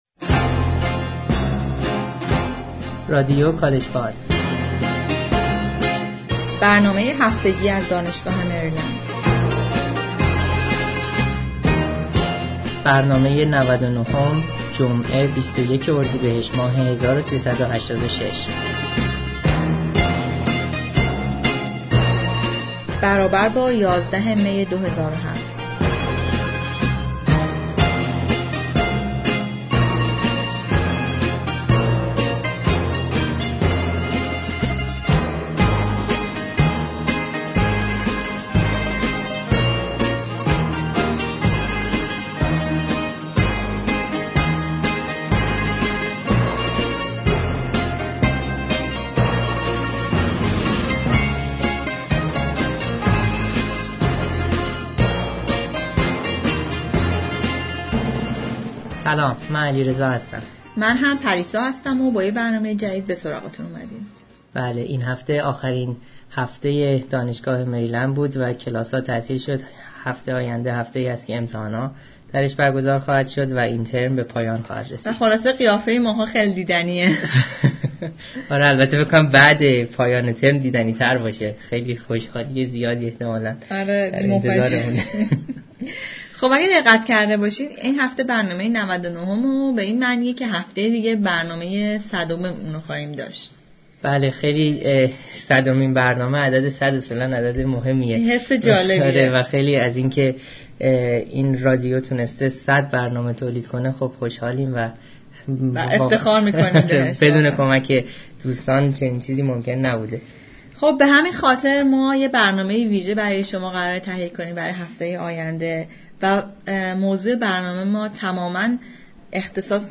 Weekly News مصاحبه